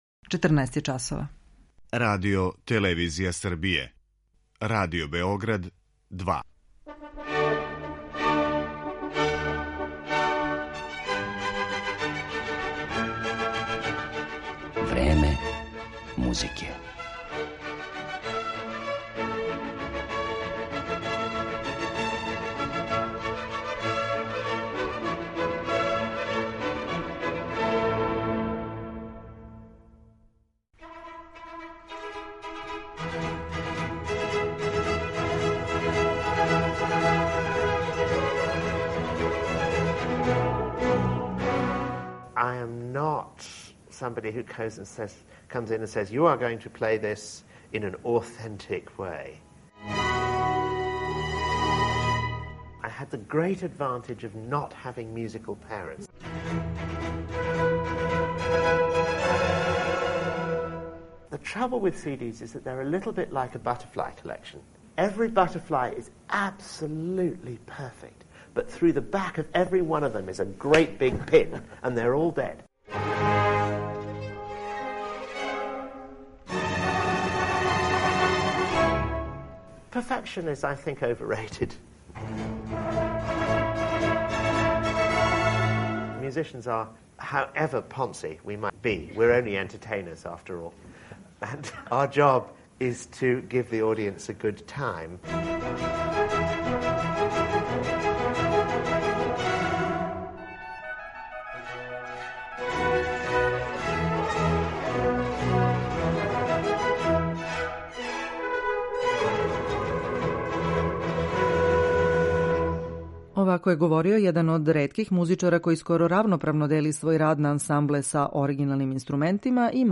Данас ћемо га ипак представити у интерпретацијама литературе са којом се прославио и слушаћемо како тумаћи дела барокних мајстора ‒ Рамоа, Хендла, Баха, Матесона, Грауна и Корелија.